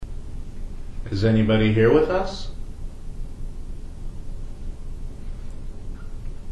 The beginning of the "word" is unclear, but it sounds like a "ck" noise at the end.
(Editor's note: We just watched the video from this session and the noise does not appear on the camera's audio -- only the digital recorder.)